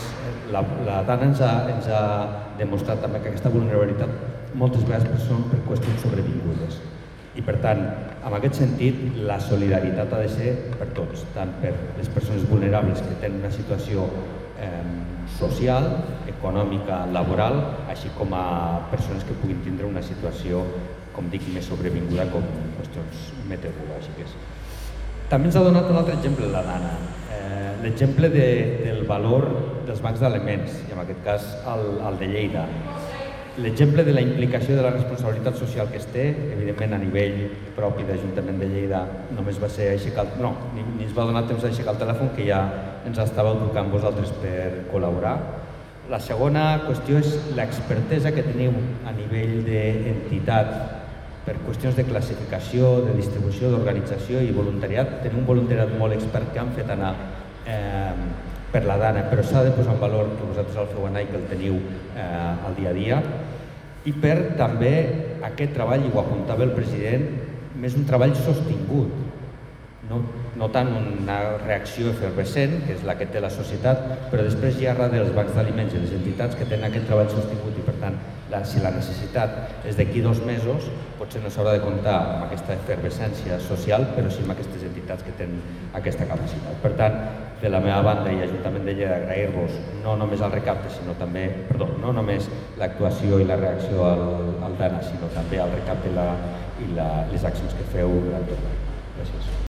Tall de veu del tinent d'alcalde, Carlos Enjuanes, sobre els prejudicis de la vulnerabilitat i la tasca del Banc dels Aliments a Lleida